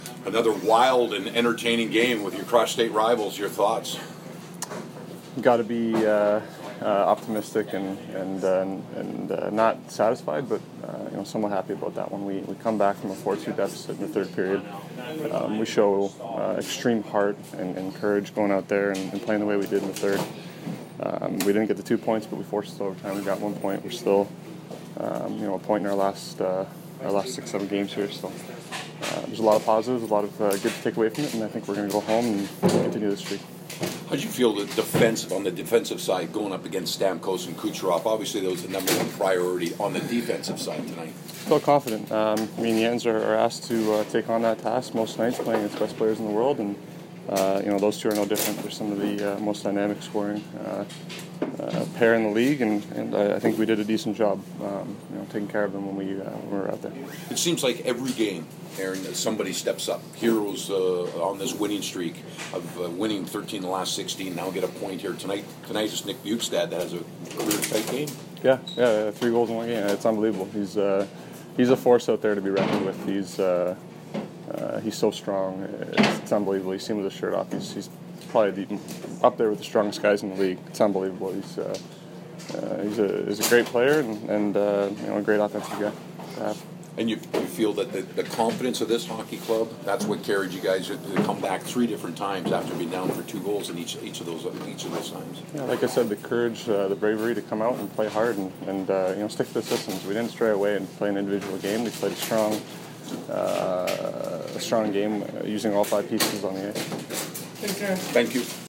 Aaron Ekblad post-game 3/6